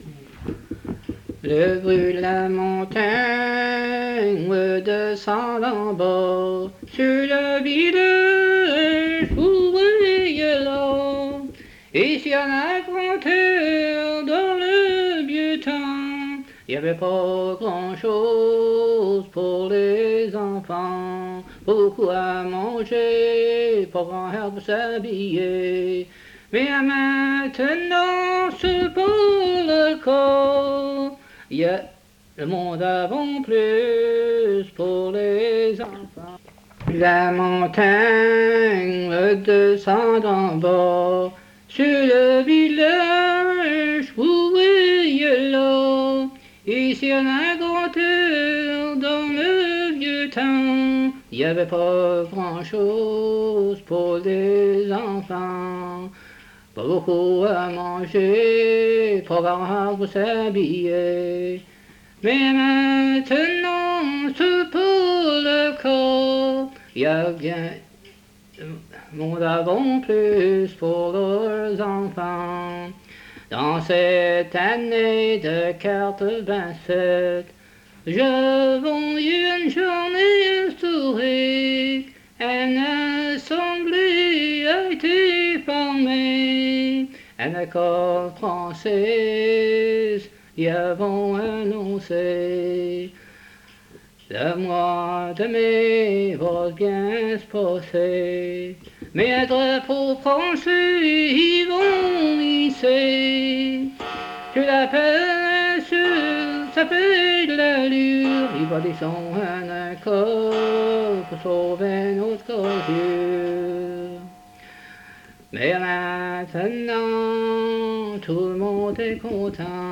Chanson Item Type Metadata
Emplacement La Grand'Terre